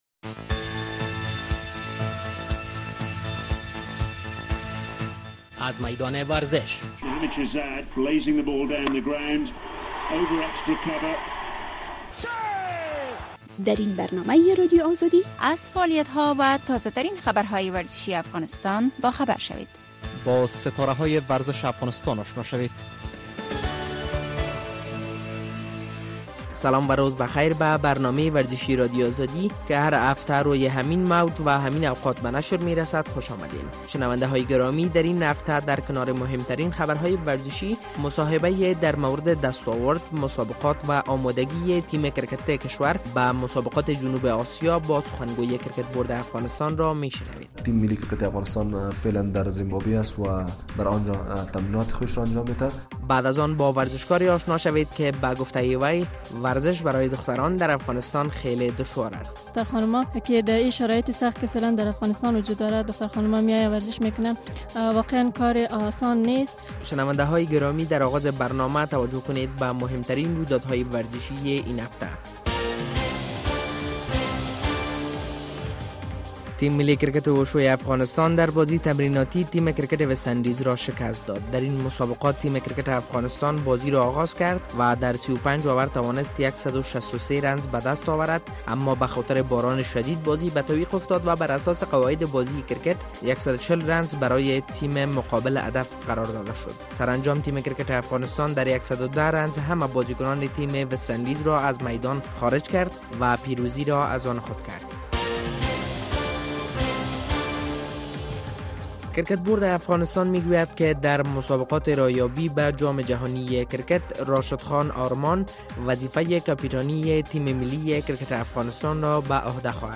درین برنامه ورزشی رادیو آزادی در نخست مهمترین خبرهای ورزشی این هفته و بعد از آن مصاحبۀ را در مورد آماده‌گی تیم ملی کرکت کشور برای مسابقات راه‌یابی به جام جهانی می‌شنوید.